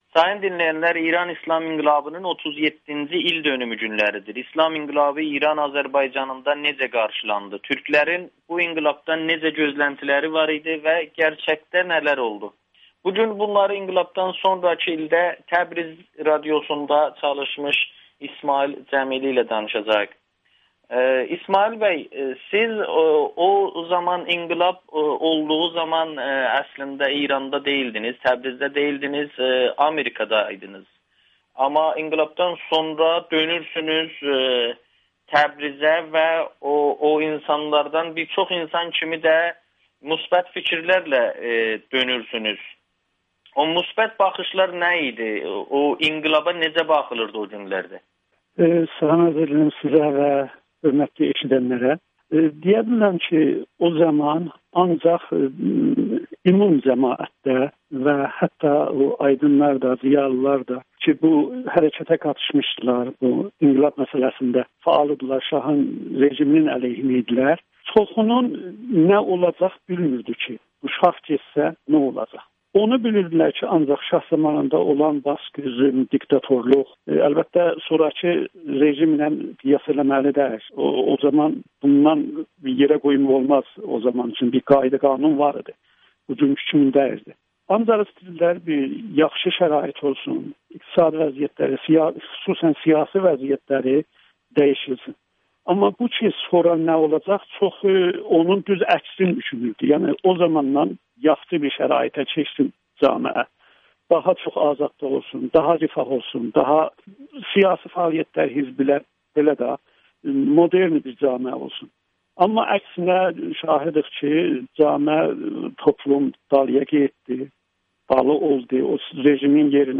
İranda Pəhləvi rejimini yıxan əsas güc türklərin gücü idi [Audio-Müsahibə]